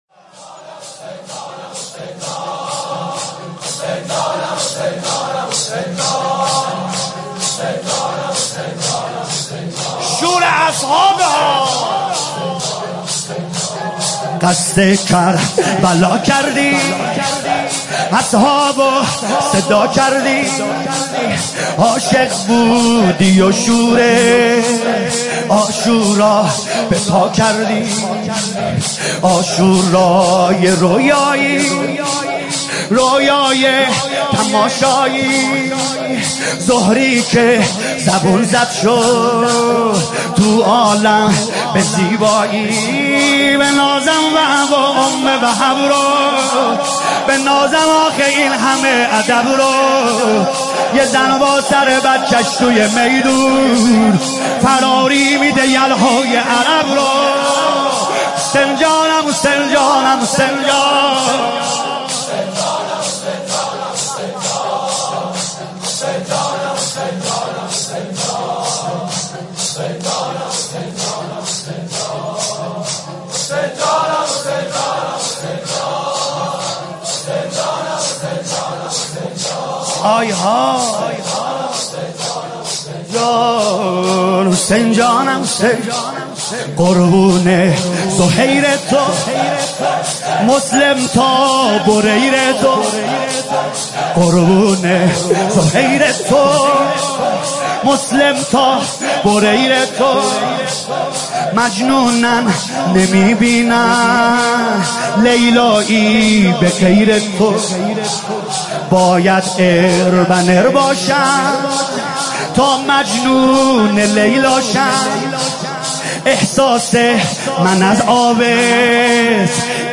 نوحه جديد
مداحی صوتی